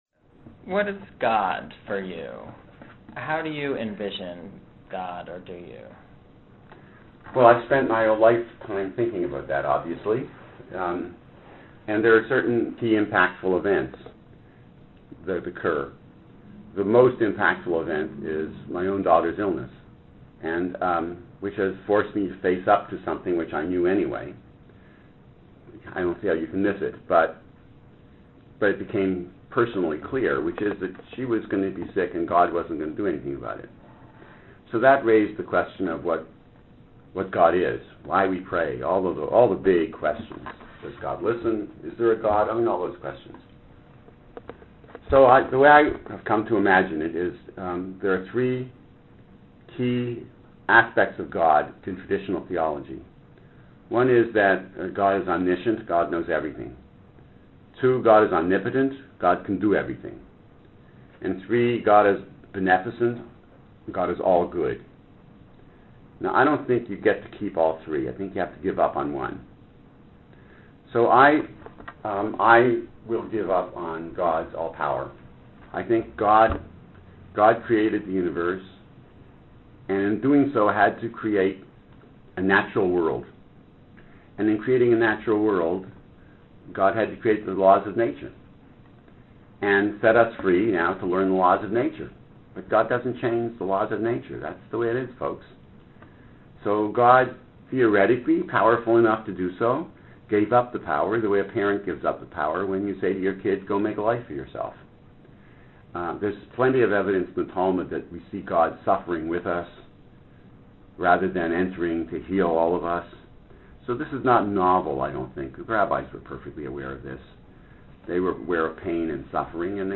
interview for Jewish Sages of Today